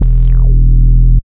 71.02 BASS.wav